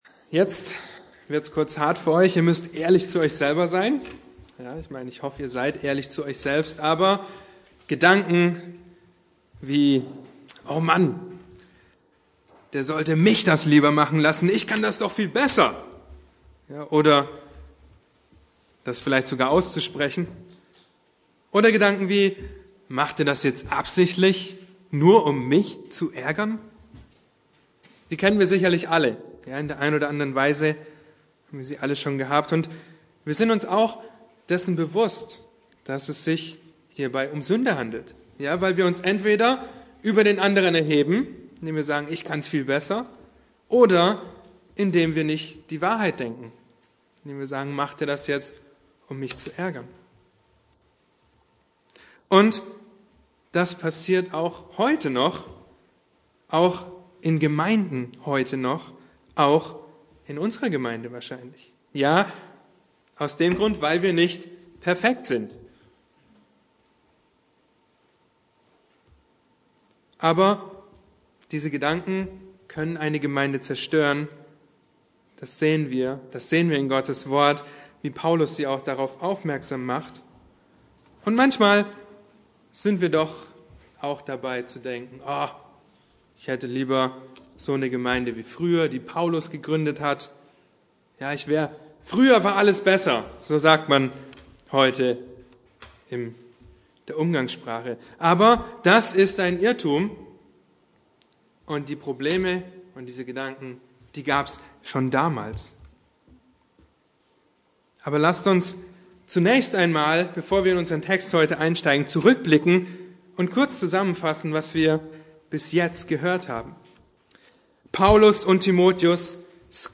Eine predigt aus der serie "Der Kern der Lehre Jesu."